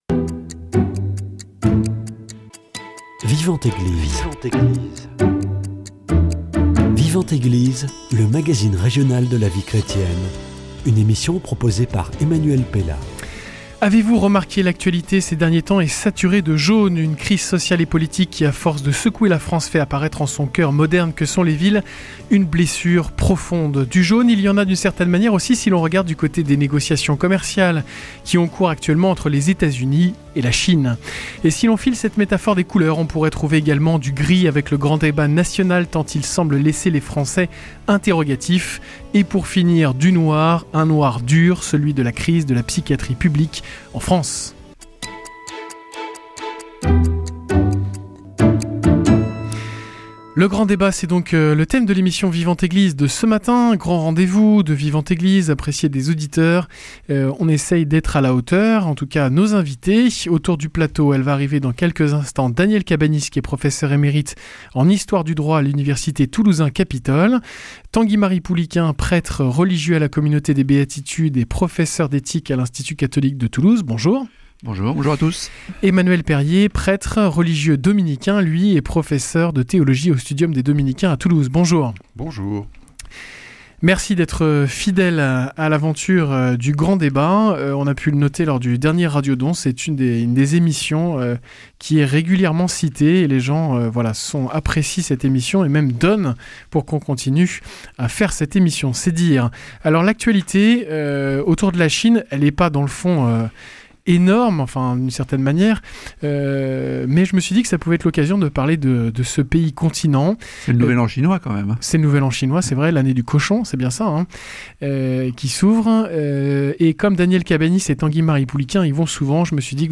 Ce matin, il s’agit de votre rendez-vous d’actualité mensuel.